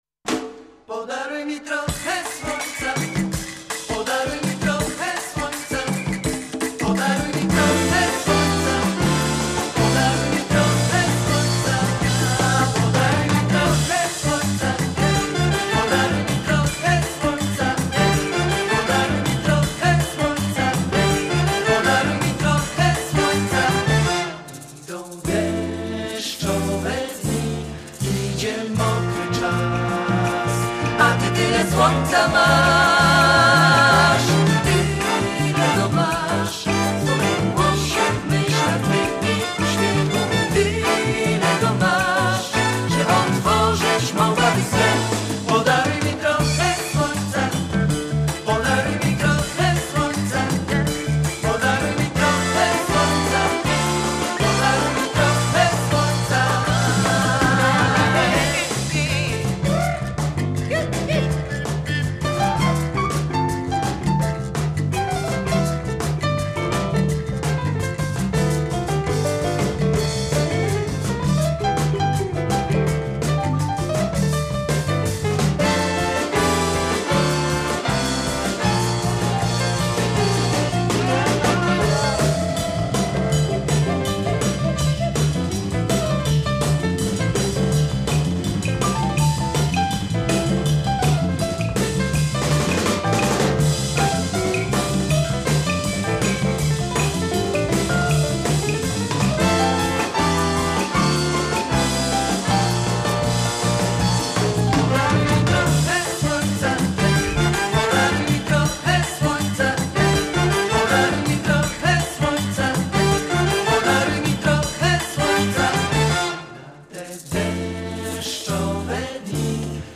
Na 77. urodziny Marka Dutkiewicza rozmowa o pisaniu hitów [ODSŁUCH]